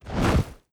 Swish Inventory.wav